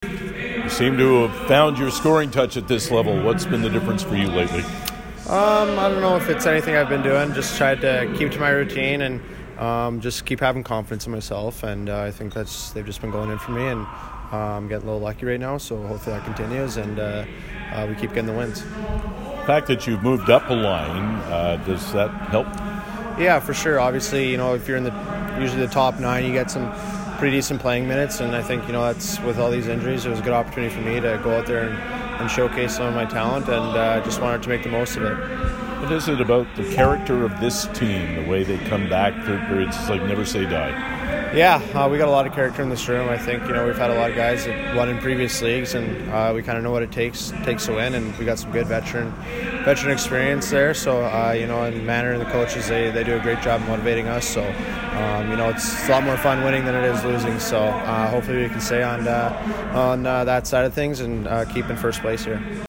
Below is CJBQ’s